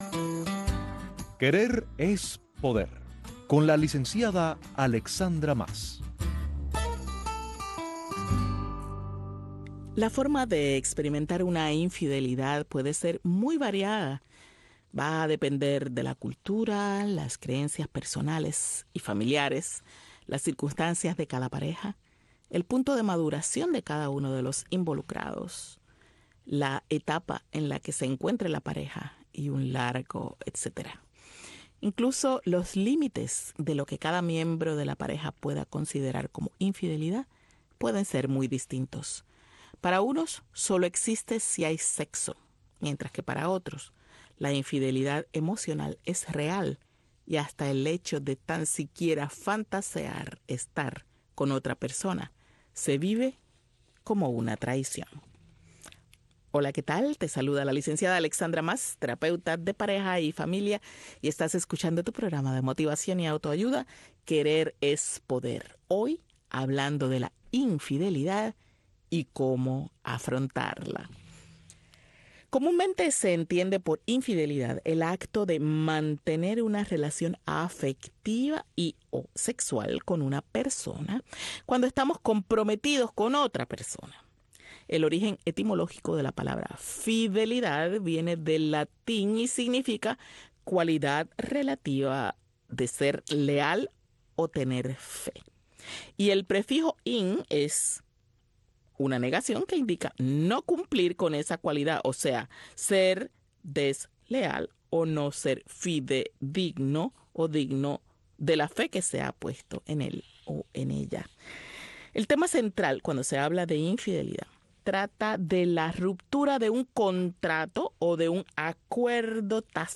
QUERER ES PODER es un programa de motivación y auto-ayuda